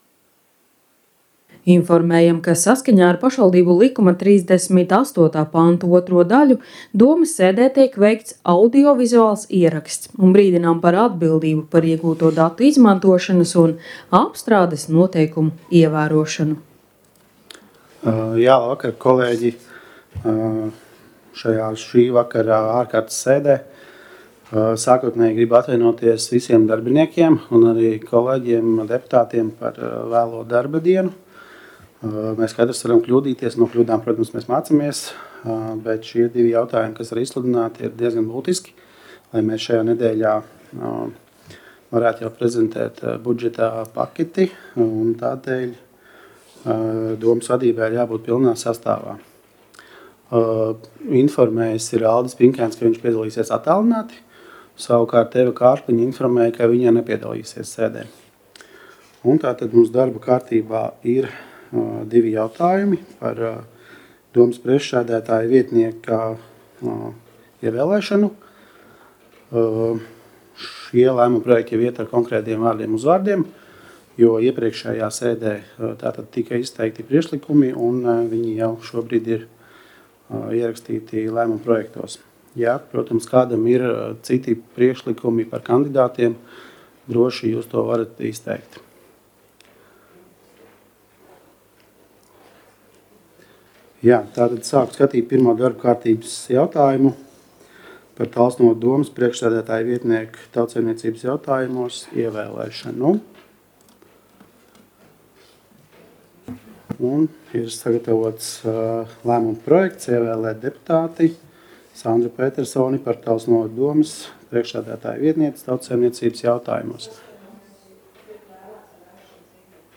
Talsu novada domes ārkārtas sēde Nr. 7
Domes sēdes audio